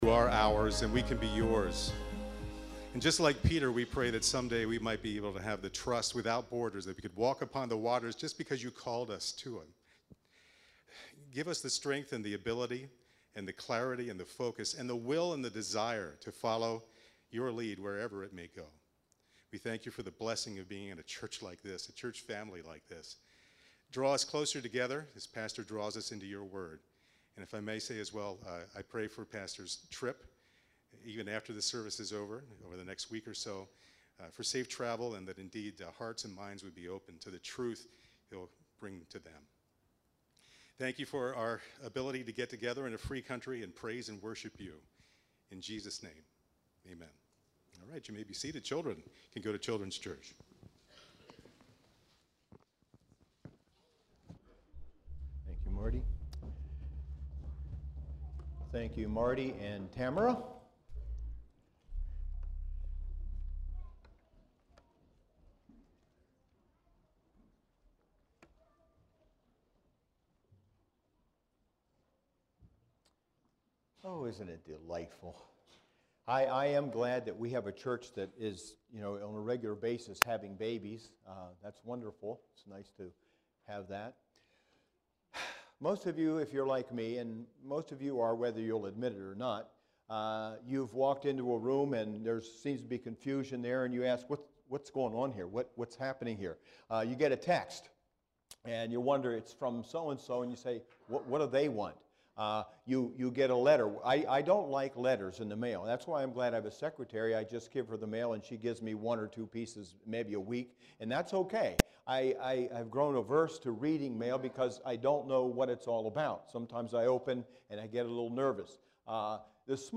SERMONS - Grace Fellowship Church